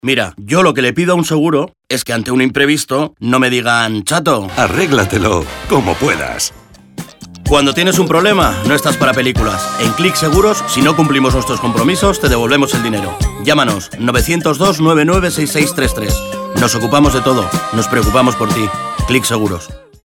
Se utilizaron cuñas de 20 segundos y menciones en directo realizadas por los conductores de los programas.
Audios de la campaña: